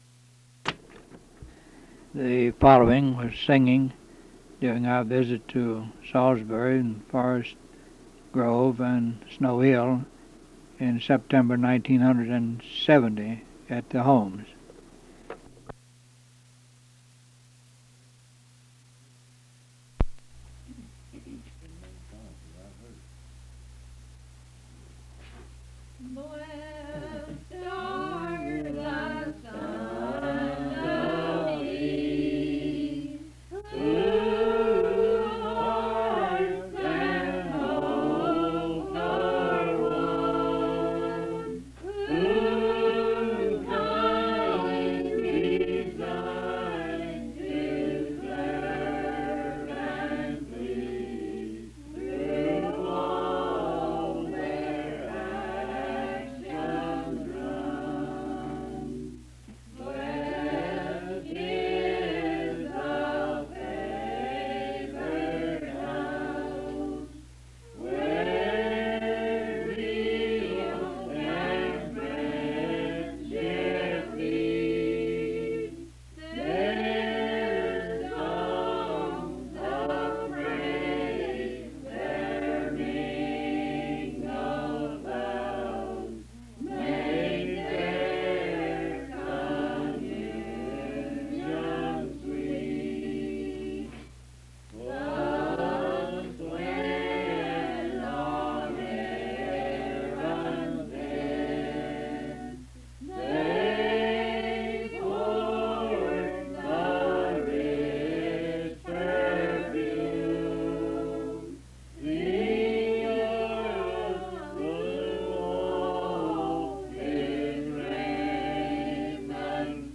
Singing